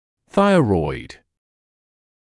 [‘θaɪrɔɪd][‘сайройд]щитовидная железа; щитовидный